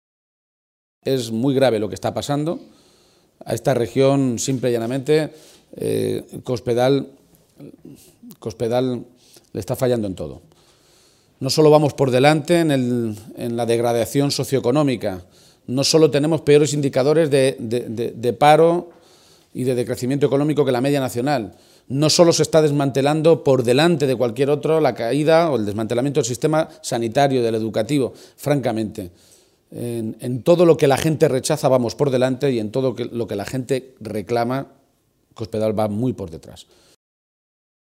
Momento de la intervención de García-Page